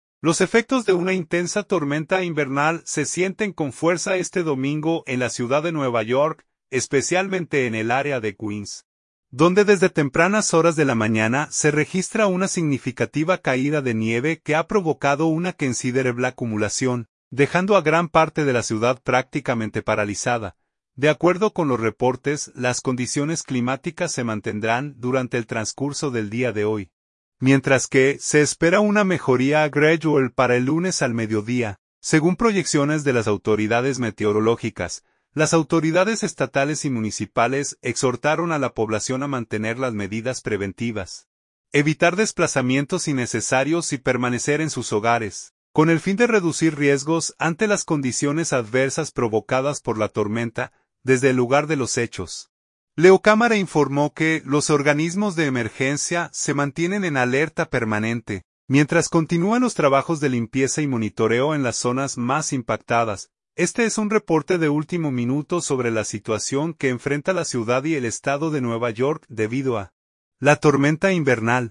Este es un reporte de último minuto sobre la situación que enfrenta la ciudad y el estado de Nueva York debido a la tormenta invernal.